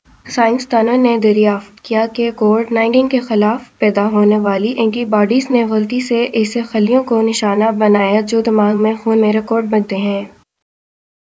Spoofed_TTS/Speaker_12/111.wav · CSALT/deepfake_detection_dataset_urdu at main